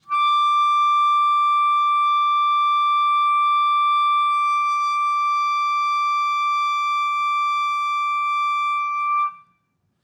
Clarinet
DCClar_susLong_D5_v2_rr1_sum.wav